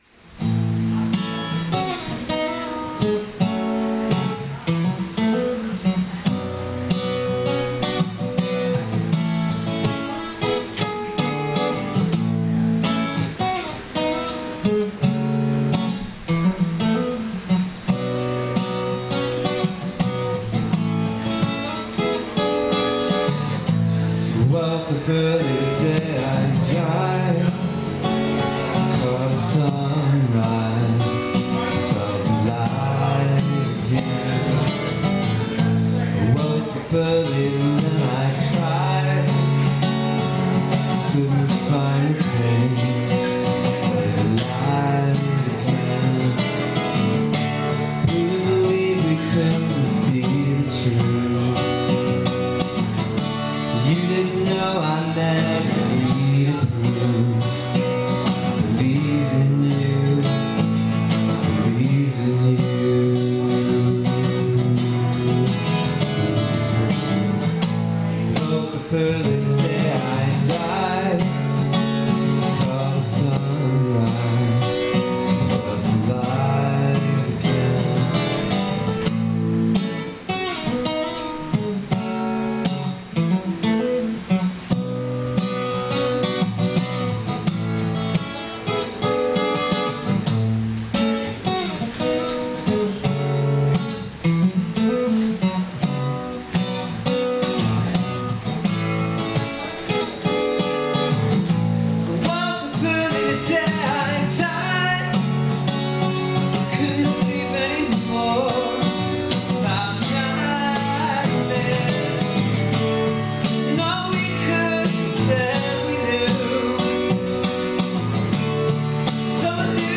splitting octaves